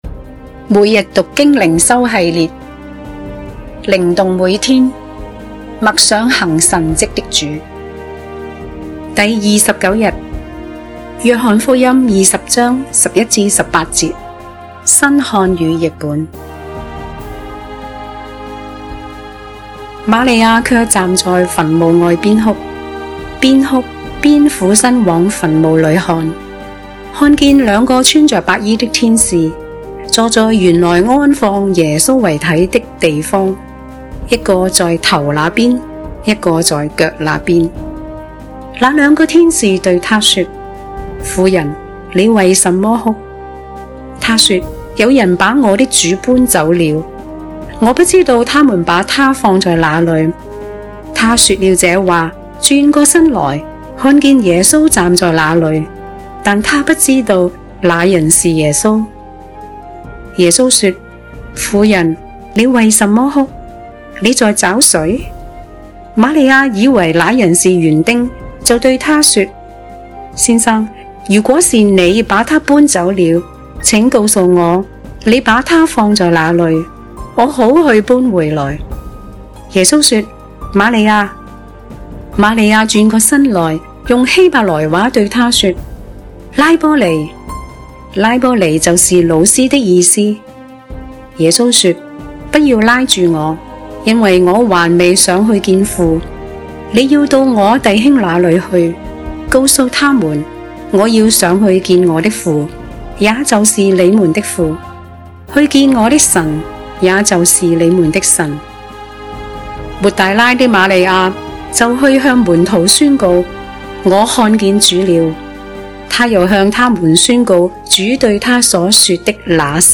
經文閱讀